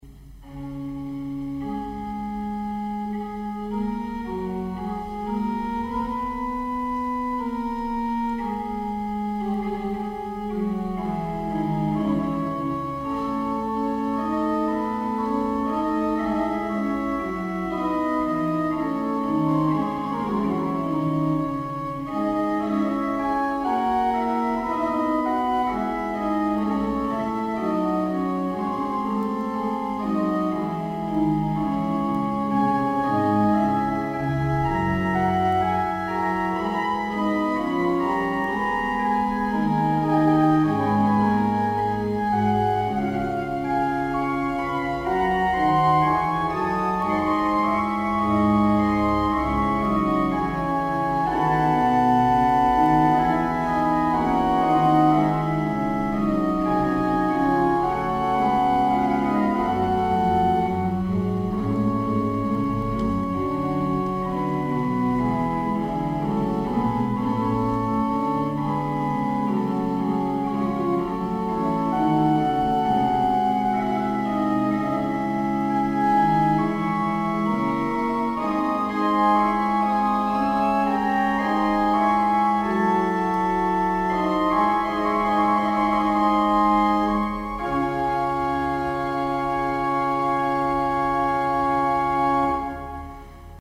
lors d'un concert à Pontoise le 21 septembre 1996